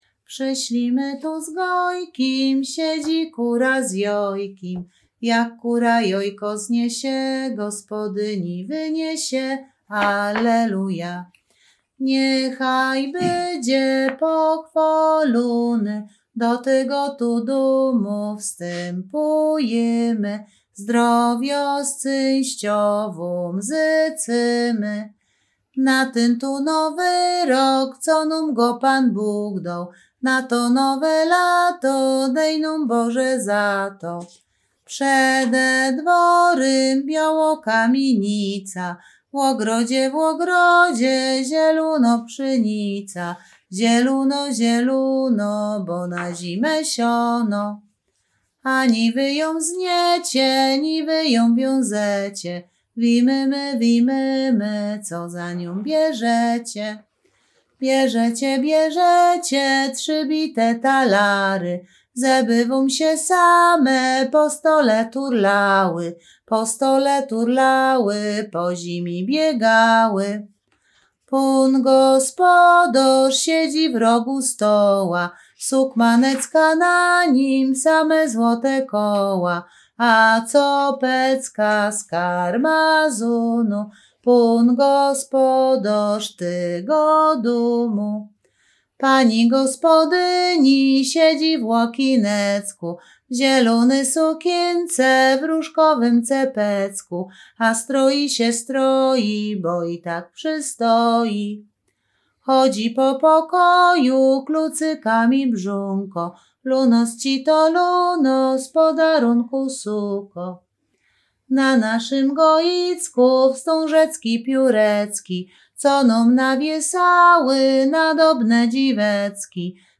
województwo łódzkie, powiat sieradzki, gmina Sieradz, wieś Chojne
Wielkanocna
Śpiewaczki z Chojnego